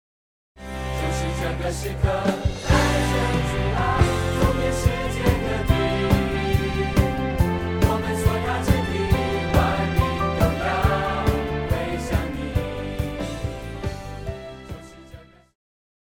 套鼓(架子鼓)
乐团
教会音乐
演奏曲
独奏与伴奏
有主奏
有节拍器